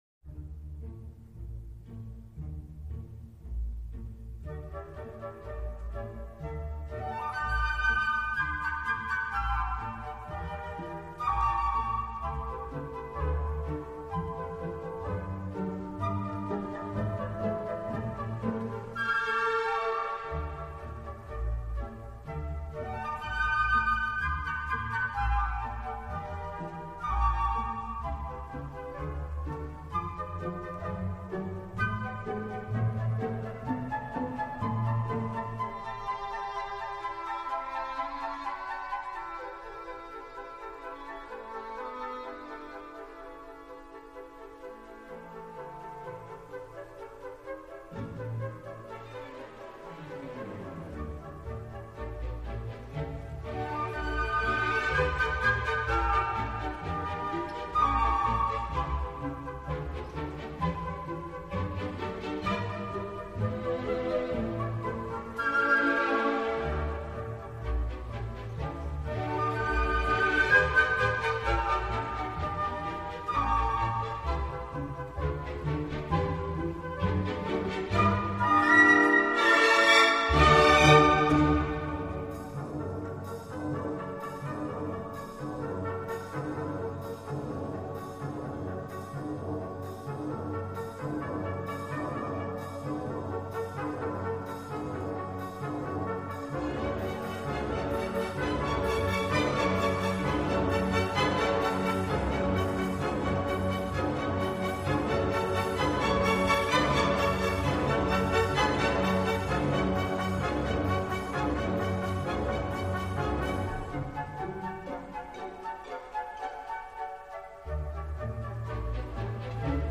Щелкунчик - Чайковский П.И. Классическая музыка величайшего композитора для взрослых и детей.